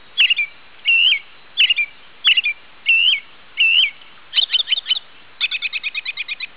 robin.wav